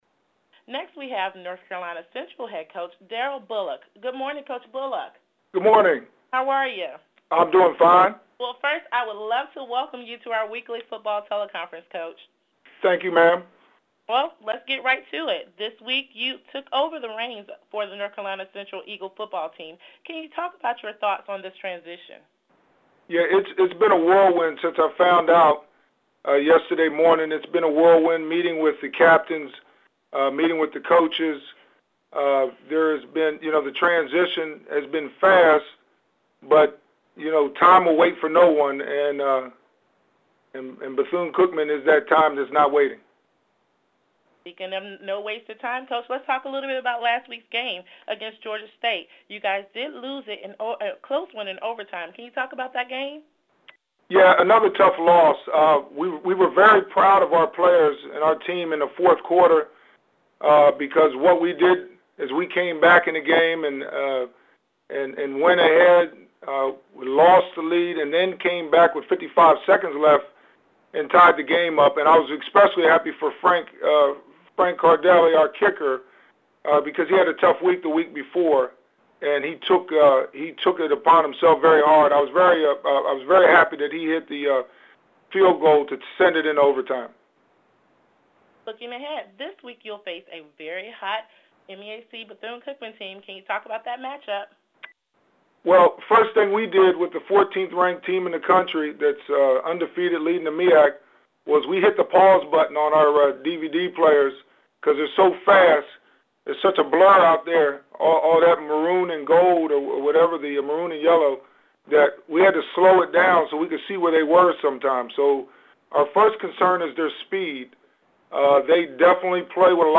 MEAC Teleconference